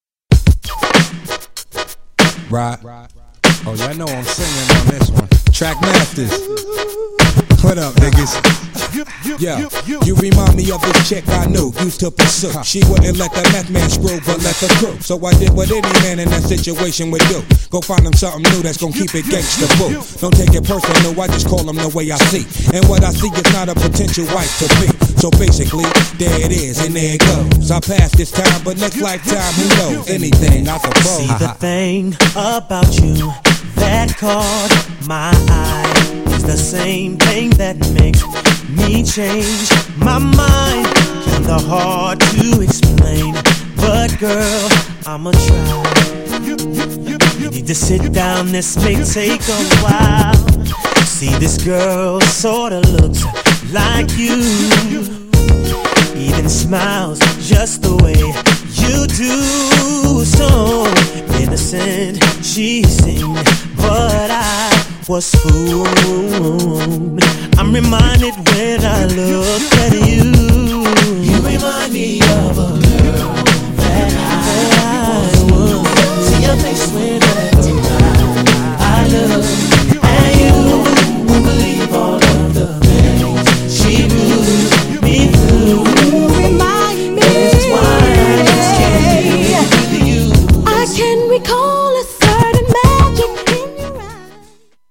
GENRE R&B
BPM 96〜100BPM
featにRAP
HIPHOPテイスト
男性VOCAL_R&B